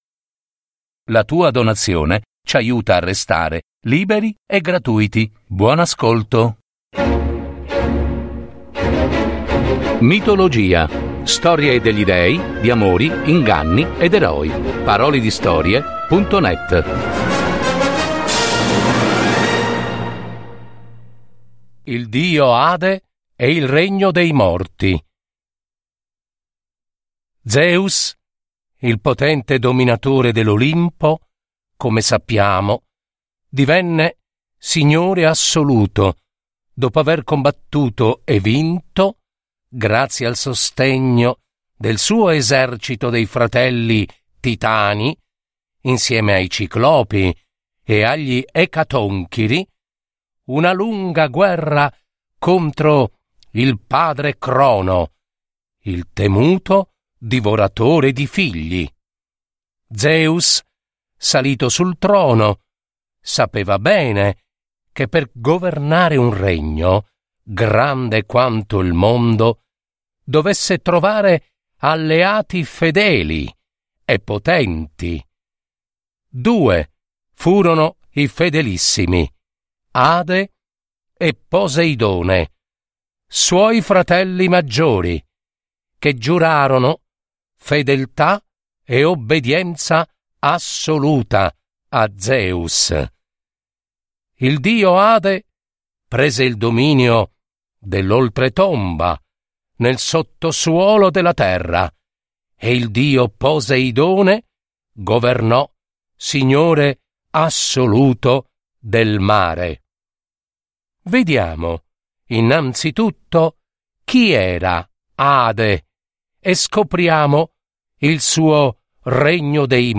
Adattamento e messa in voce